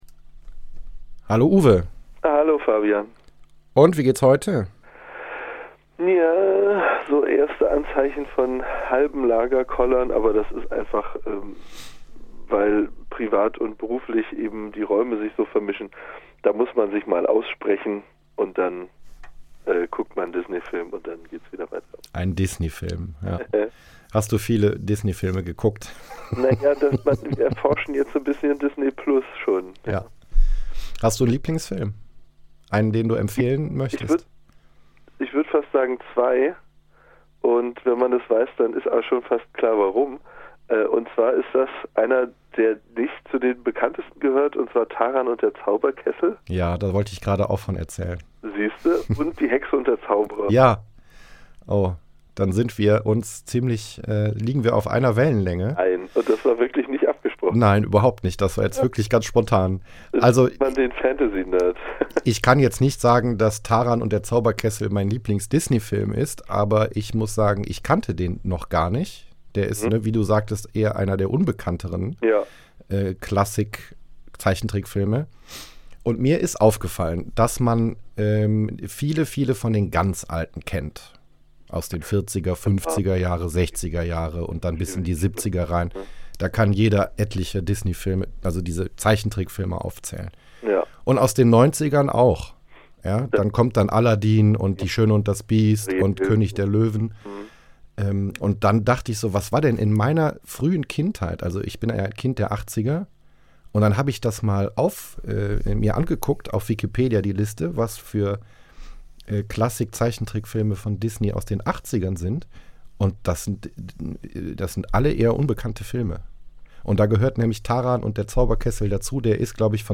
Austausch über das Telefon. Diese Erfahrung teilen sie mit Euch: Offtopic, QandA und was Ihr schon immer mal wissen wolltet.